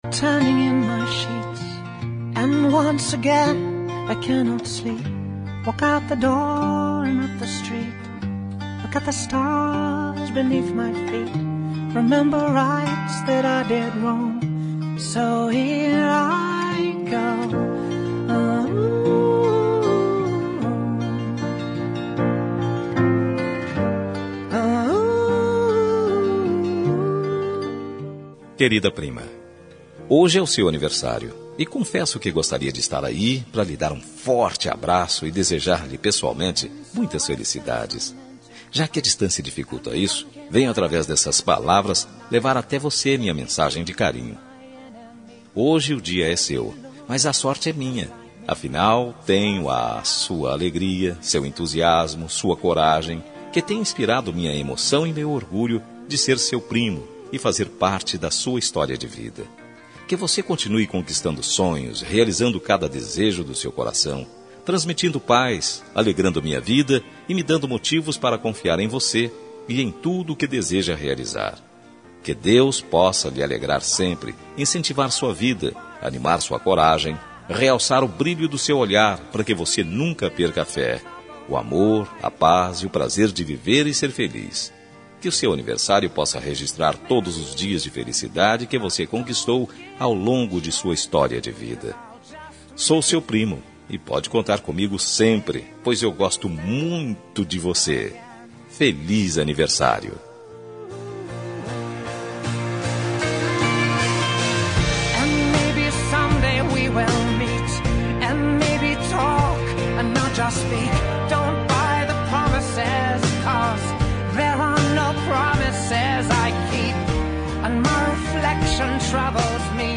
Aniversário de Prima – Voz Masculina – Cód: 042821 – Distante